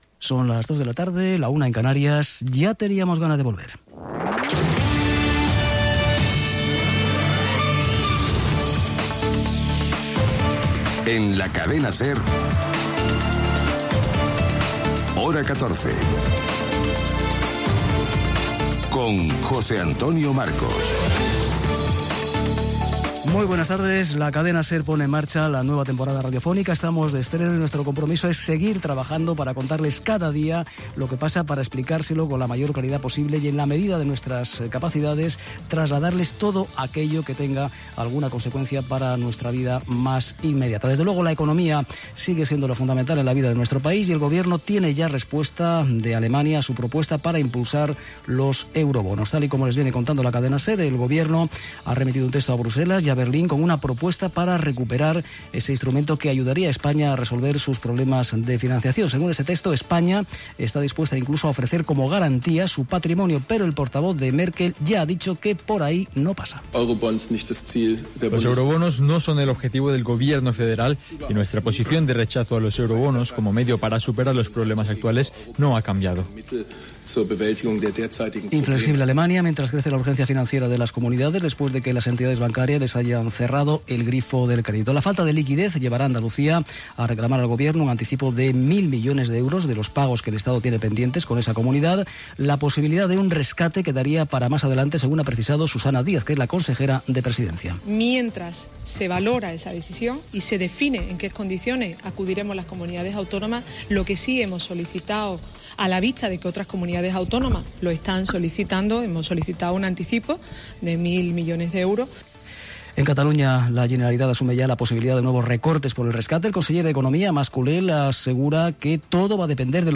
Informatiu
Primer dia que es fa servir aquesta versió instrumental de la sintonia de la cadena SER per als serveis informatius.